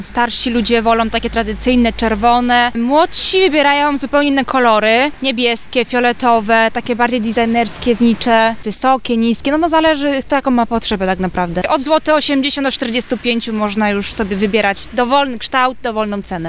O to, jakie znicze cieszą się największą popularnością i ile za nie zapłacimy, zapytaliśmy sprzedawców.